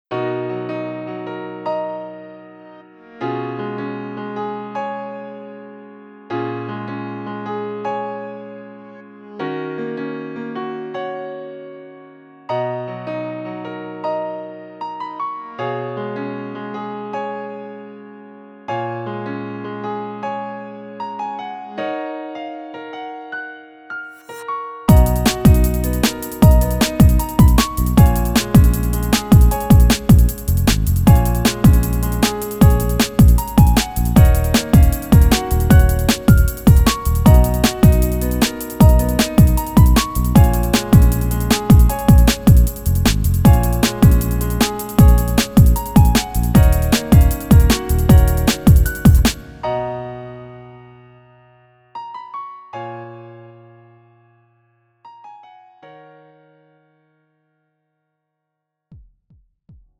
음정 (-1키)
장르 가요 구분 Lite MR